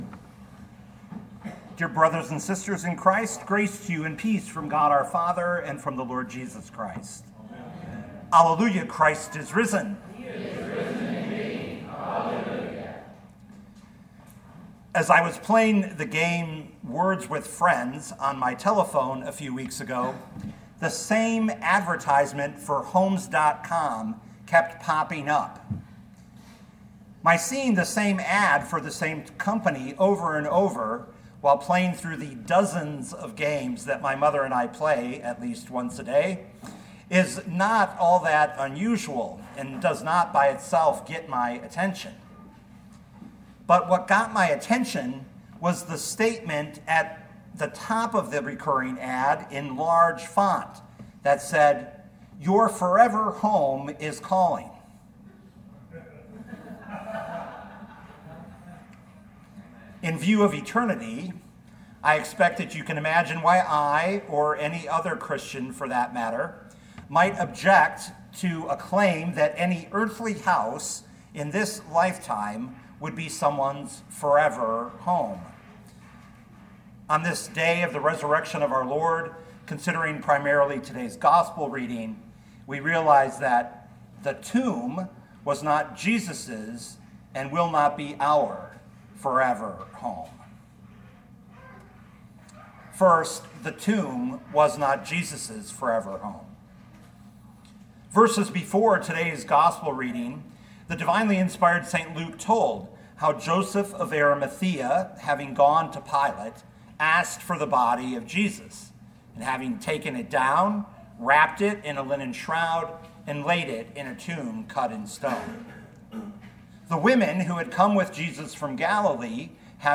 2025 Luke 24:1-12 Listen to the sermon with the player below, or, download the audio.